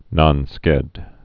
(nŏnskĕd)